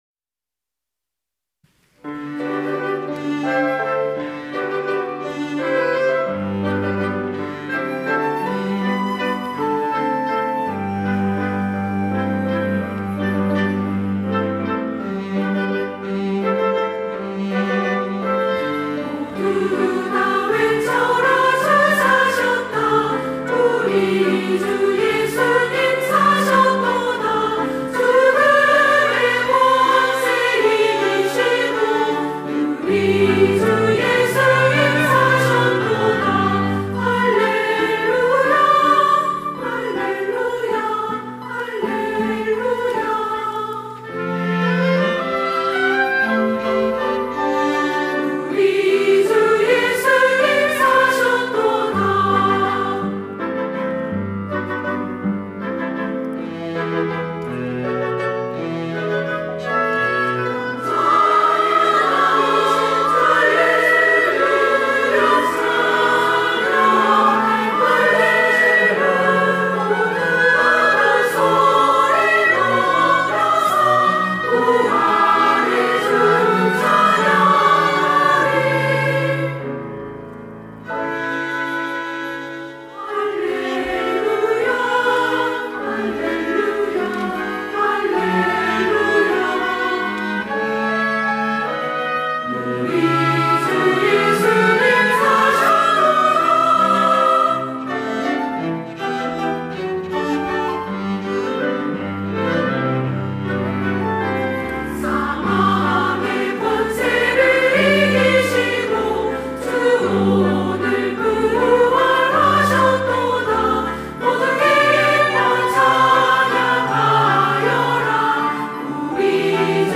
찬양대 여전도회